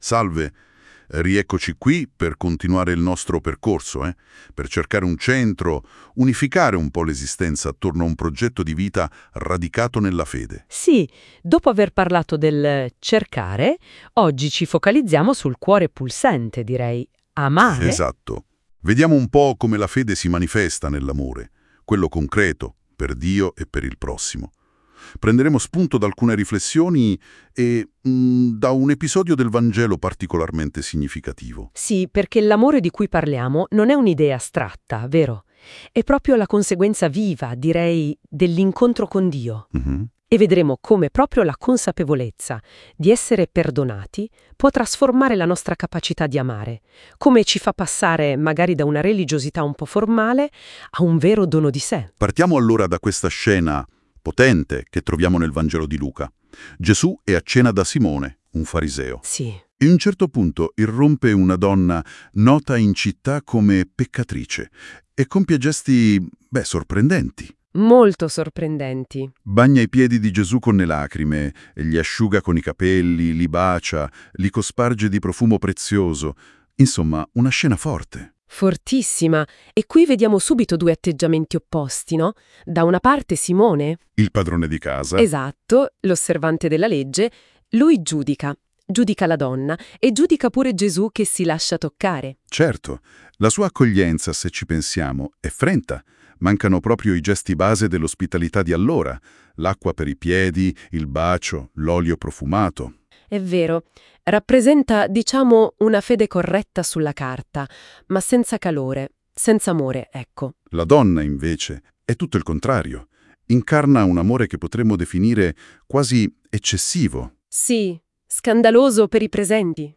Essi - sulla base del materiale presentato - elaborano i contenuti stessi in forma accattivante: podcast e video sono elaborati dall'AI "NOTEBOOKLM".